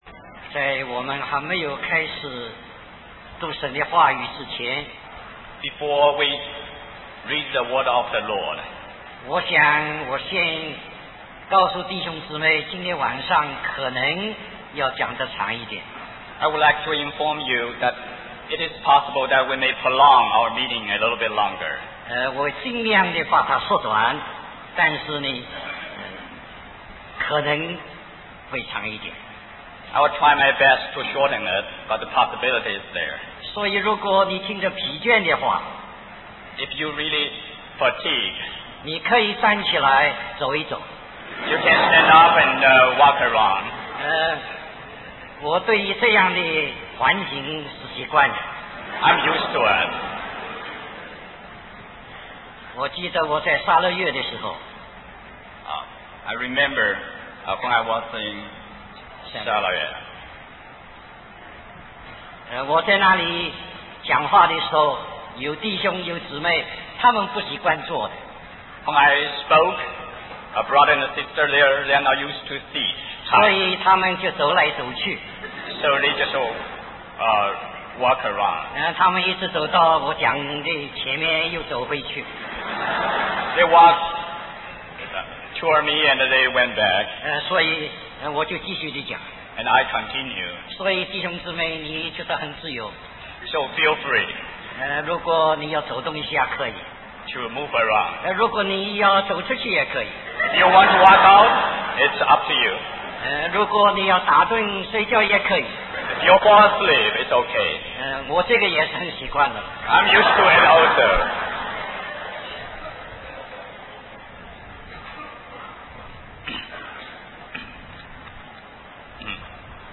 In this sermon, the speaker encourages the audience to feel free to move around and not be fatigued during the meeting. He then references the book of Revelation, specifically chapter 1, verses 9-19, which talks about the Christian life and the importance of living in a righteous manner.